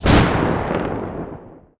EXPLOSION_Arcade_08_mono.wav